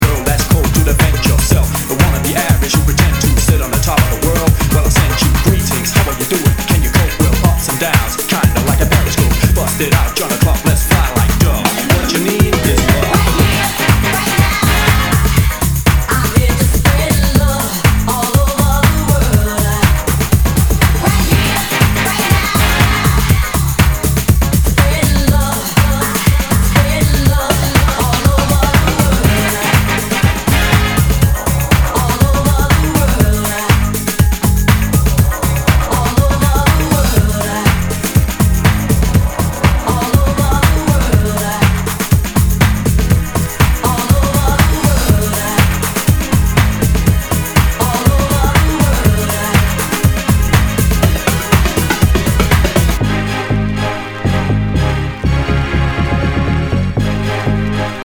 HOUSE/TECHNO/ELECTRO
ナイス！ヒップ・ハウス！
全体に大きくチリノイズが入ります